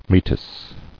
[Me·tis]